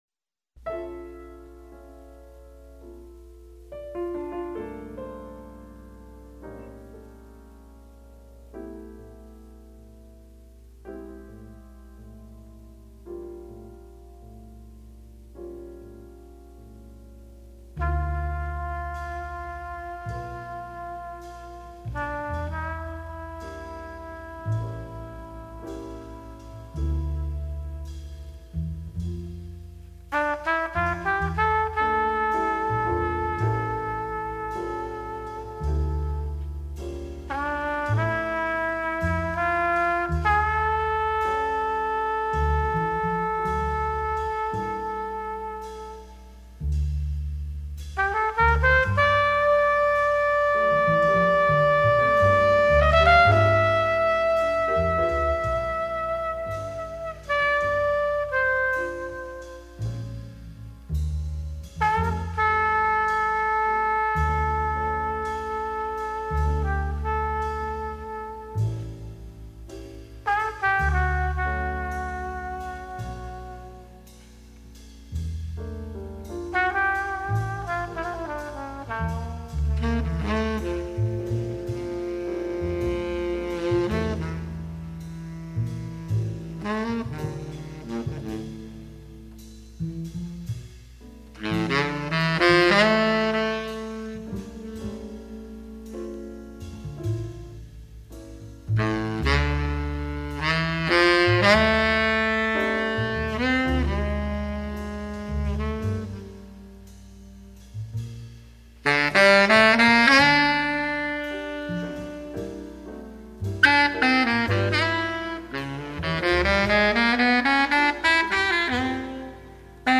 Cool Jazz, Hard Bop